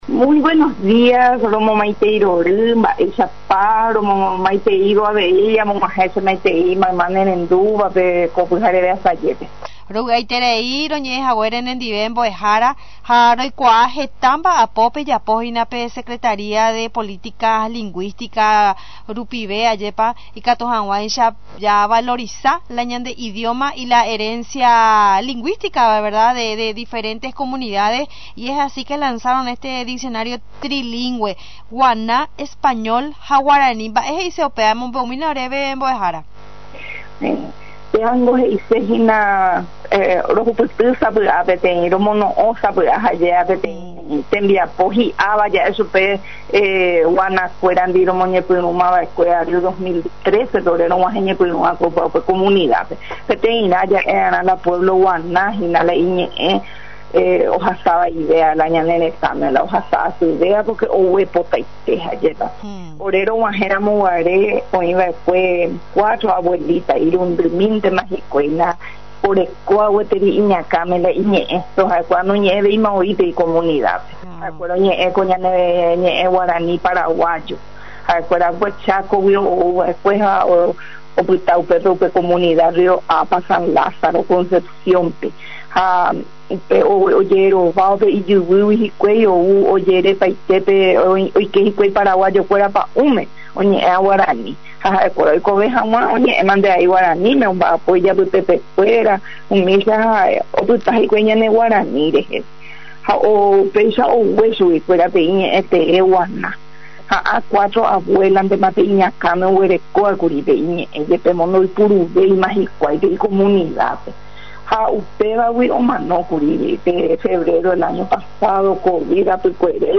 Ladislaa Alcaraz de Silvero Ministra de la Secretaría de Politicas Linguisticas, habló en una entrevista con nuestra emisora sobre el trabajo valiosísimo realizado, ko tembiapo hi’ava ja’e chupe hina, guaná kuerandive, rohupytyva romoñepyrûma vaekue 2013, comenzó diciendo sobre este importante trabajo con las comunidades indigenas.